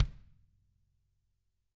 Index of /90_sSampleCDs/ILIO - Double Platinum Drums 1/CD4/Partition A/TAMA KICK D